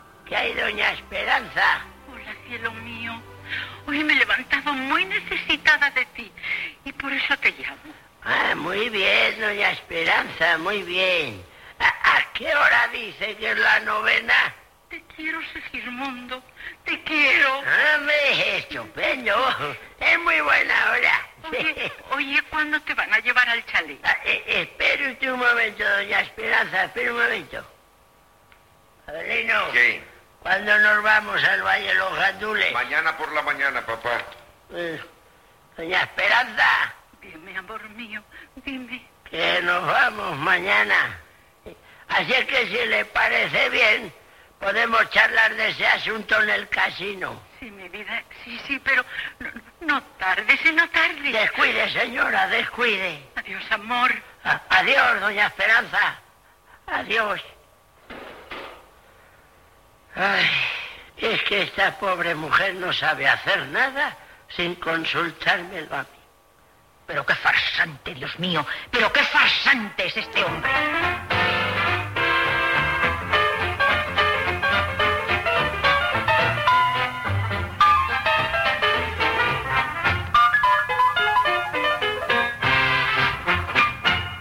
Fragment del serial. Diàleg entre Doña Esperanza i l'avi Segismundo. Música del programa.
Ficció